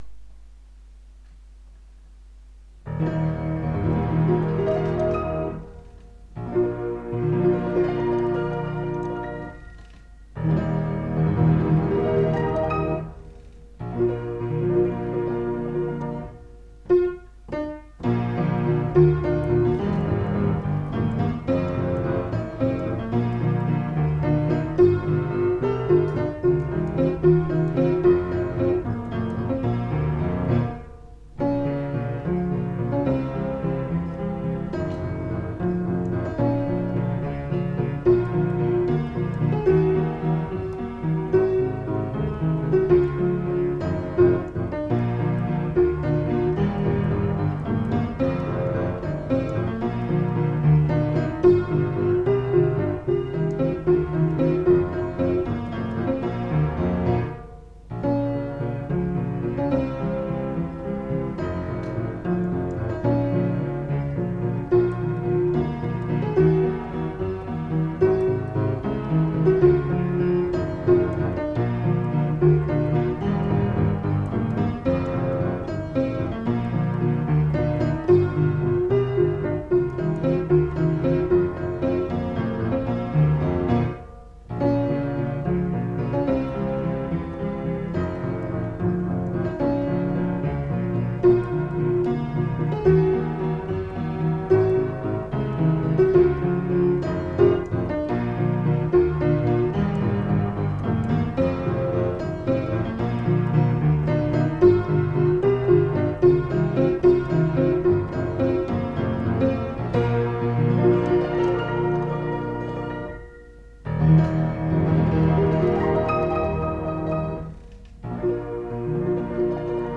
А на сайт пришлось занести мелодии в исполнении, со словами.